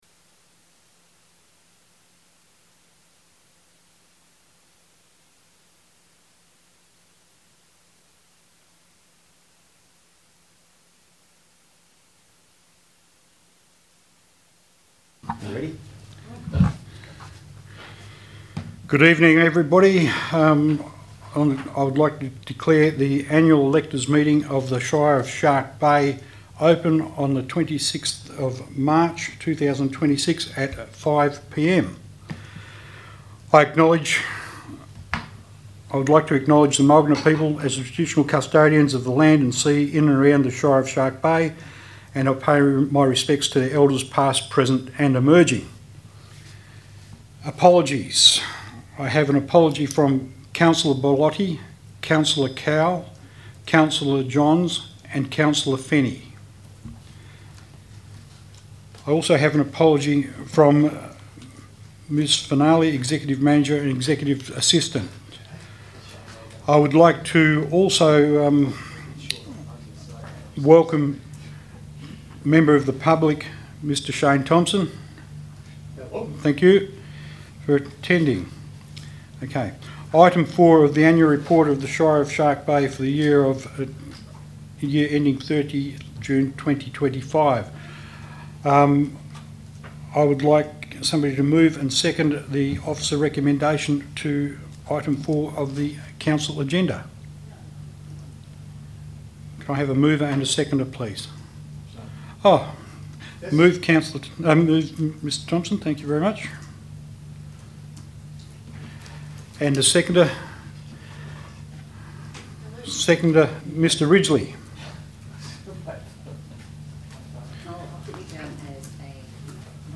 Annual Elector Meeting
Meetings are held in the Council Chambers, Shark Bay Recreation Centre, Francis Road Denham.